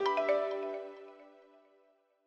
Longhorn 8 - Notify Messaging.wav